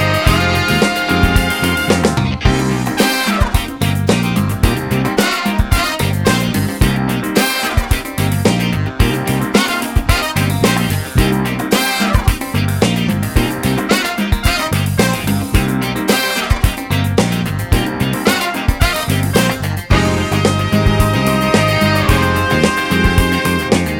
No Backing Vocals No Guitars Disco 3:12 Buy £1.50